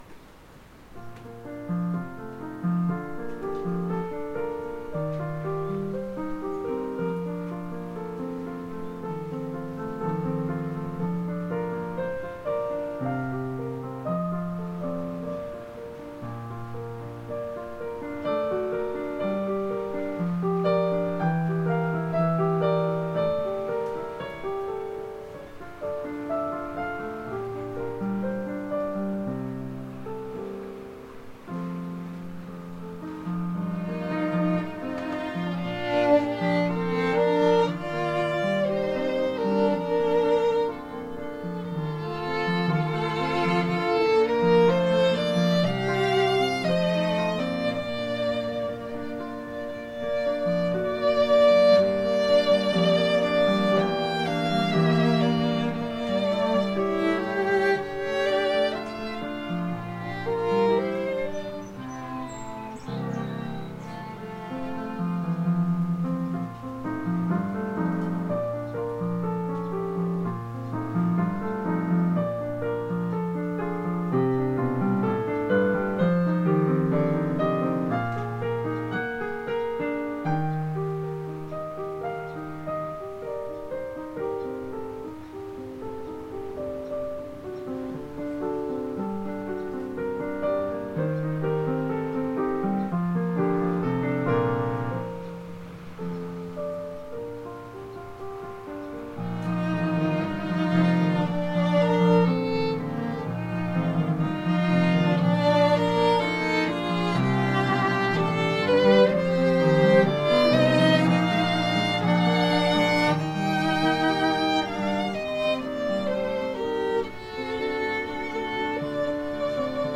the students
Chamber Groups
Andante con moto tranquillo